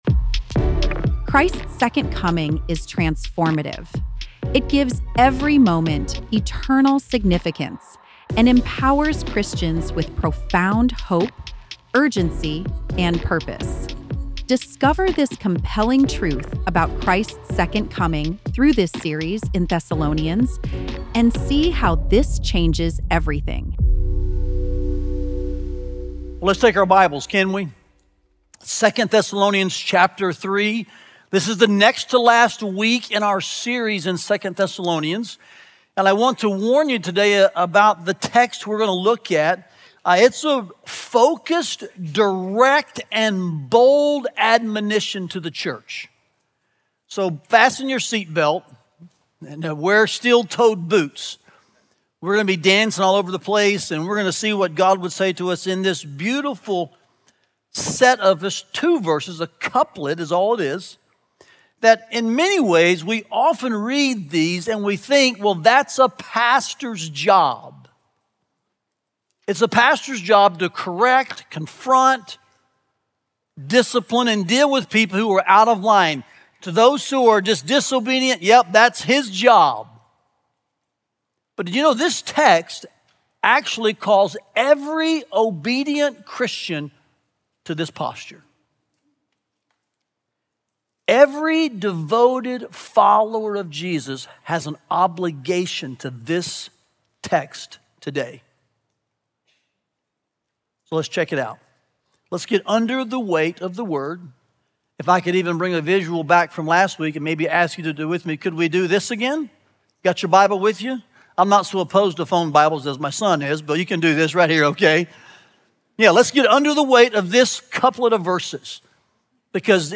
Listen to the latest sermon and learn more about this preaching series here.